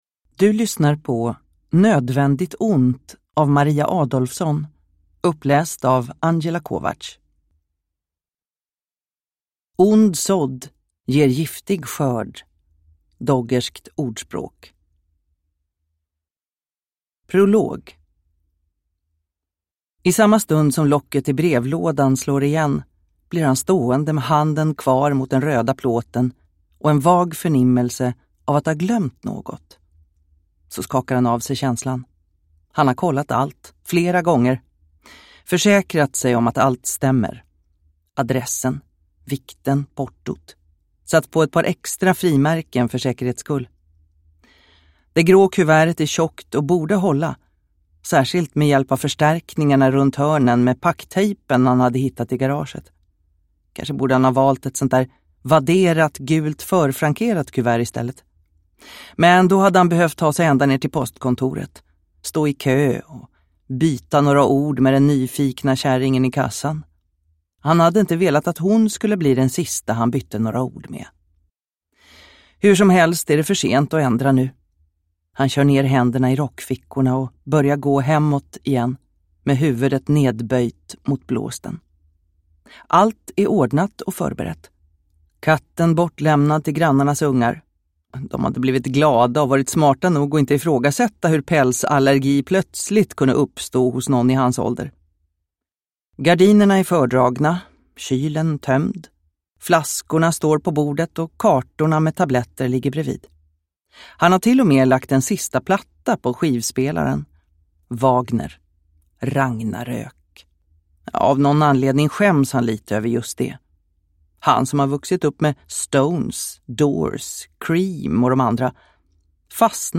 Nödvändigt ont – Ljudbok – Laddas ner